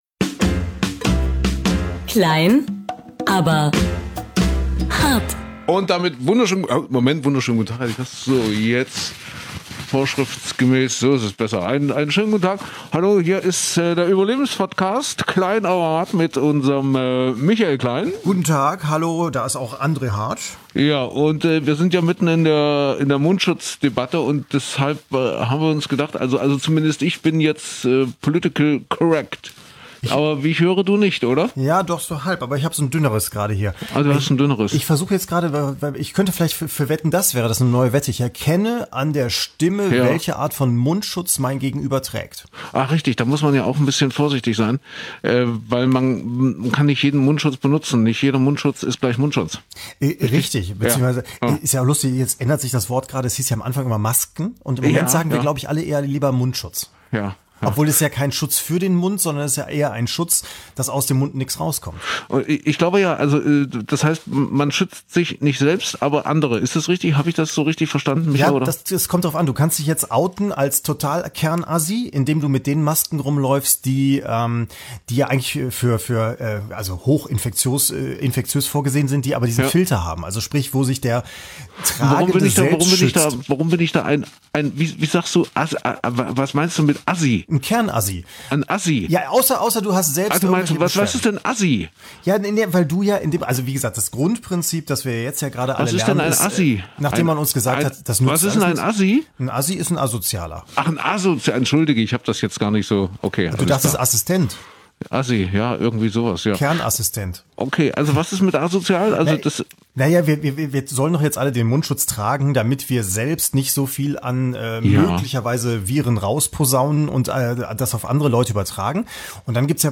Immer mit einem leichten Augenzwinkern und Diskussionspotential.